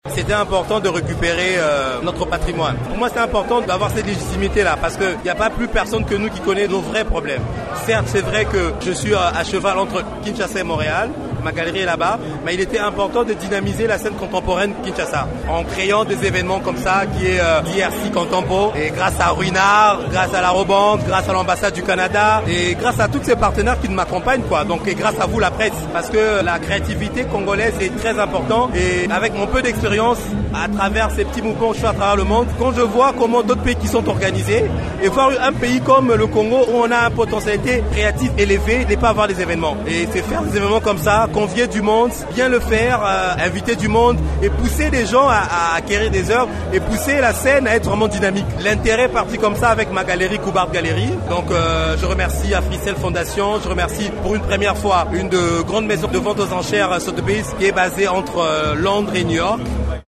explique sa motivation d’organiser cette exposition au micro